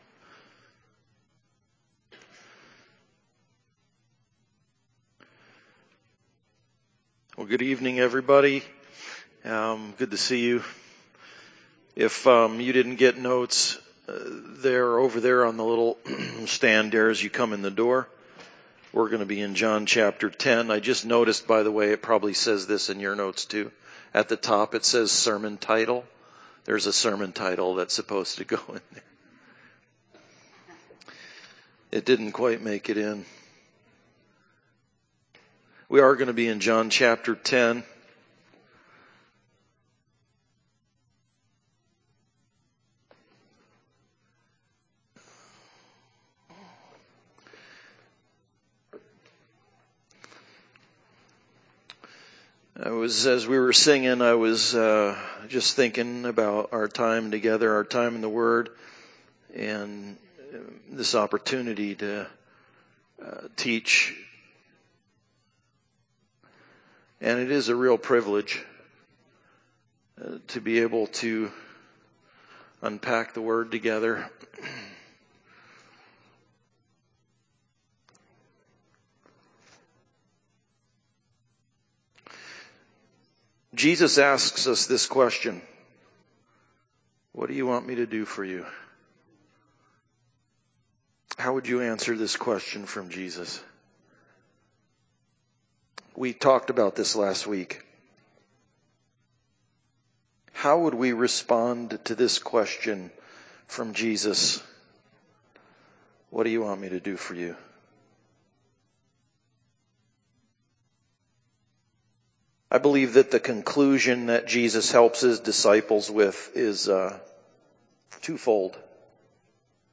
Passage: John 10:1-18 Service Type: Sunday Service Bible Text